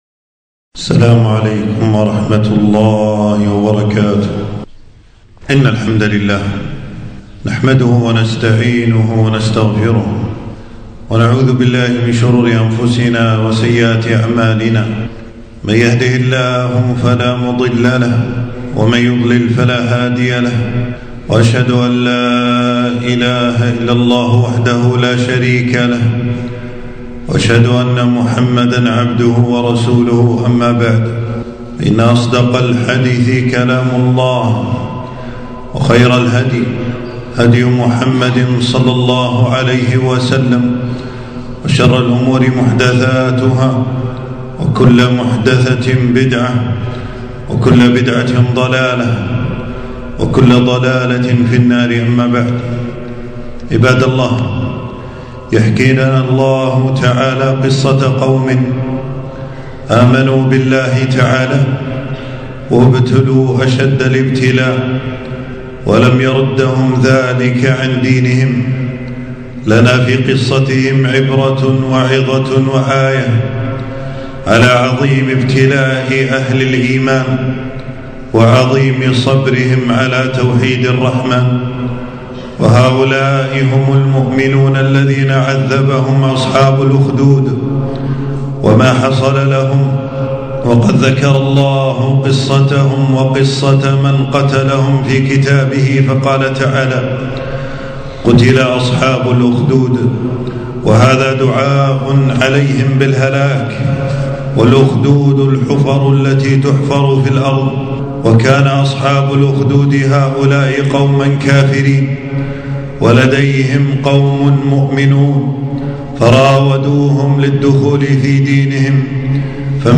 خطبة - قتل أصحاب الأخدود